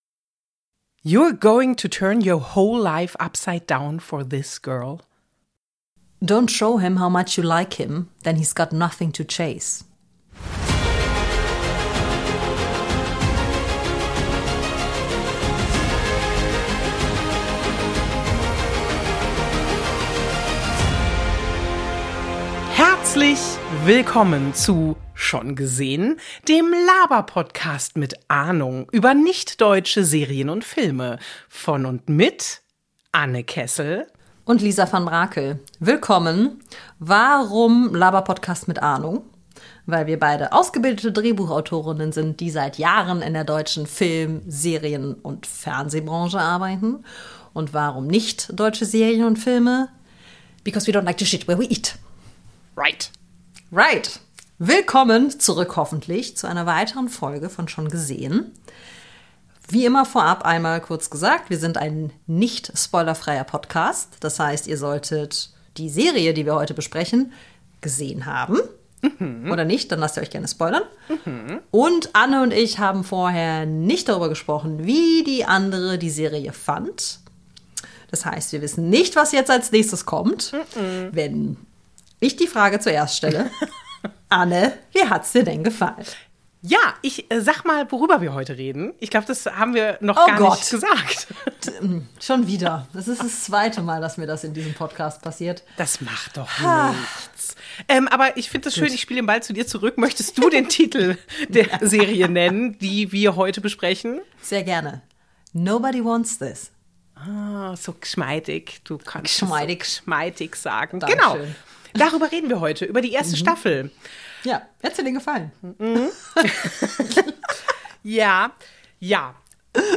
Oder ist das Genre an sich nicht irgendwie reaktionär? Die beiden Drehbuchautorinnen haben viele Fragen: Warum sind die beiden Protagonisten eigentlich zusammen?